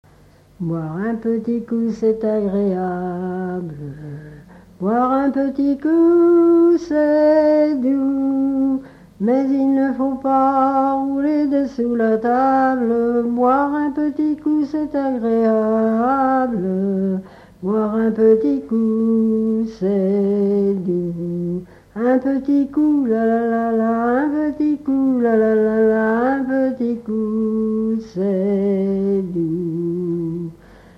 Mémoires et Patrimoines vivants - RaddO est une base de données d'archives iconographiques et sonores.
circonstance : bachique
Genre laisse
Pièce musicale inédite